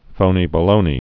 (fōnē-bə-lōnē) Slang